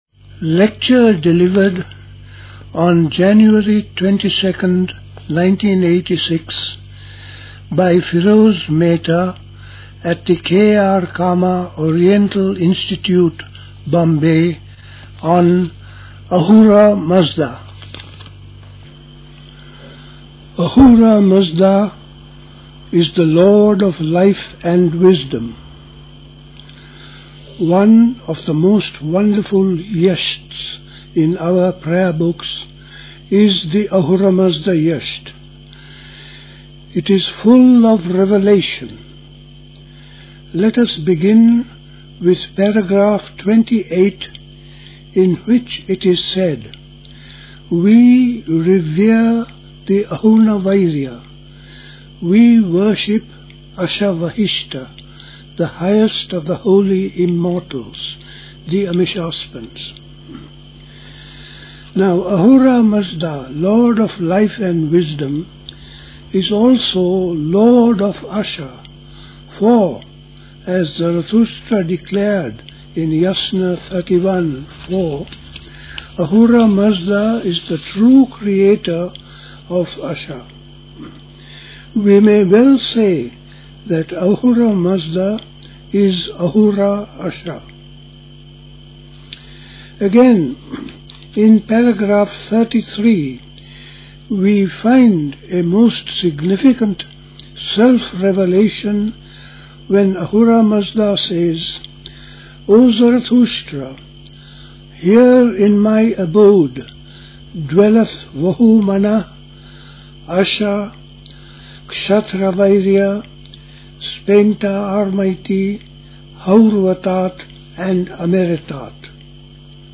Play Talk
Recorded in Bombay.